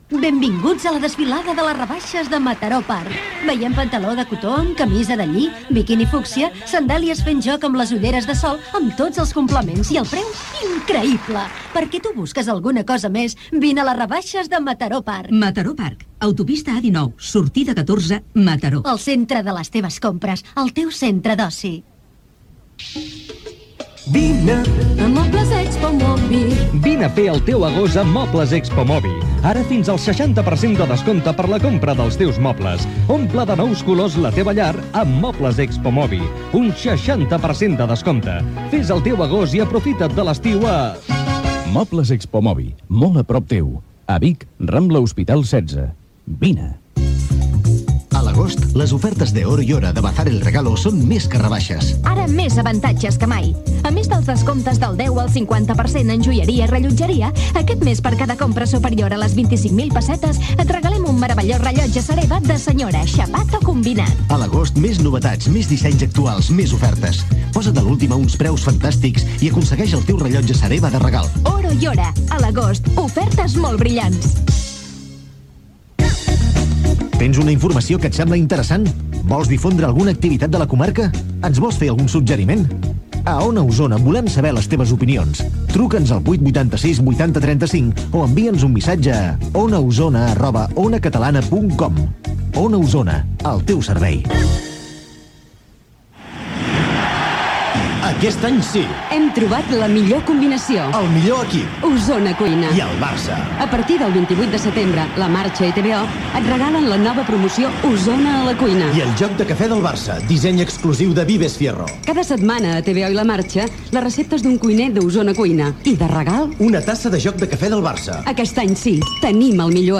Publicitat, adreça de l'emissora, publicitat, indicatiu de la cadena, indicatiu de l'emissora.
FM